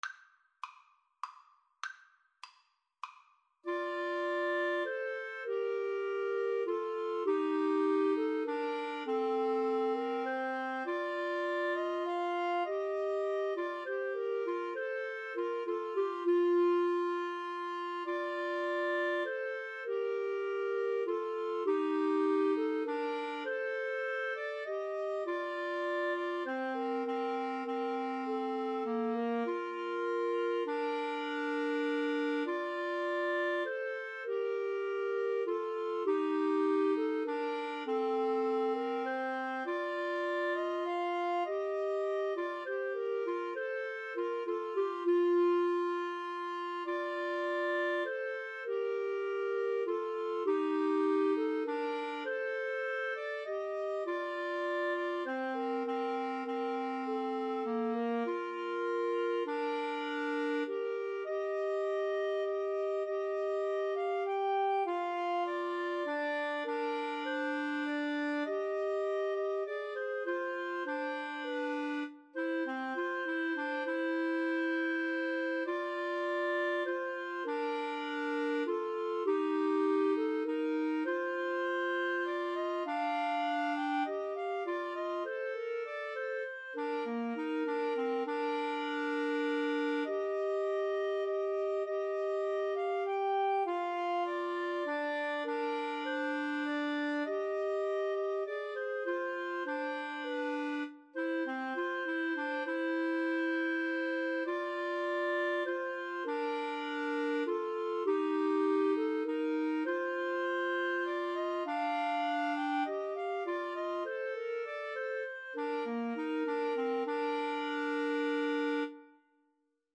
Bb major (Sounding Pitch) C major (Clarinet in Bb) (View more Bb major Music for Clarinet Trio )
3/4 (View more 3/4 Music)
Clarinet Trio  (View more Intermediate Clarinet Trio Music)
Classical (View more Classical Clarinet Trio Music)